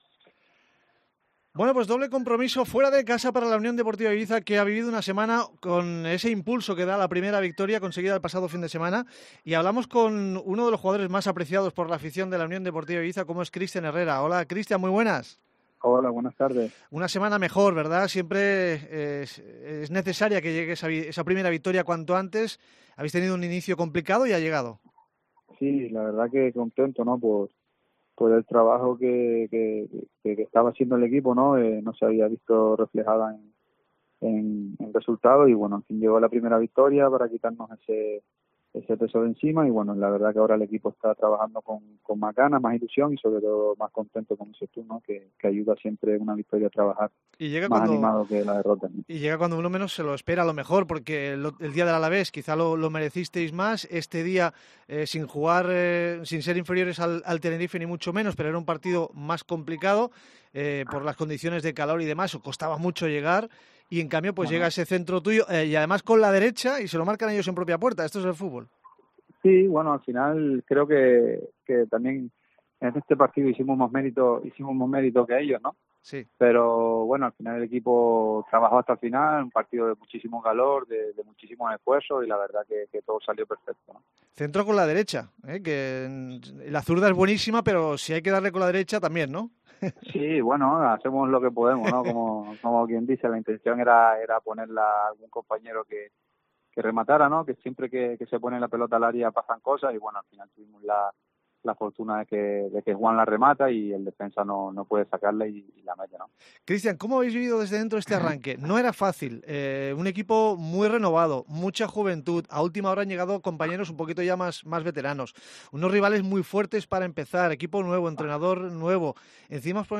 Hablamos con Cristian Herrera tras la primera victoria del equipo esta temporada (1-0 al Tenerife) sobre el nuevo proyecto, el difícil arranque y sus impresiones sobre el equipo de esta temporada.